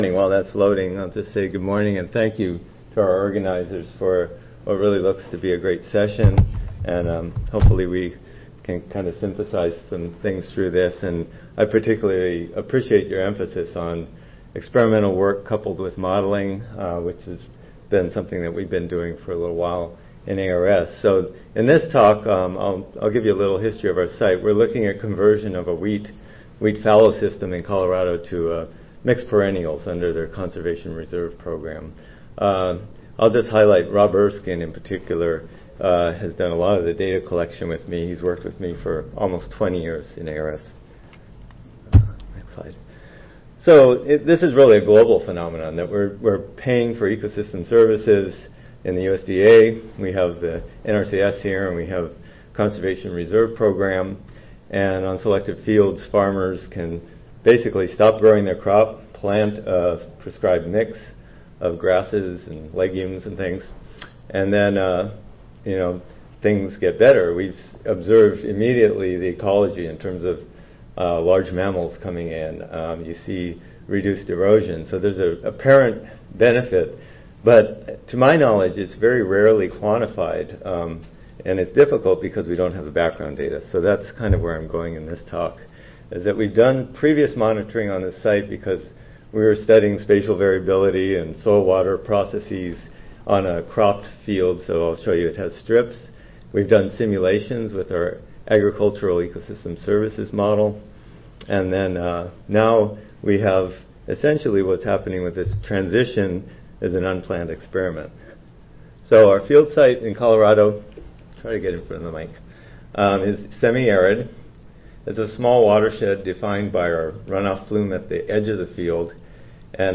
See more from this Division: ASA Section: Climatology and Modeling See more from this Session: Examples of Model Applications in Field Research Oral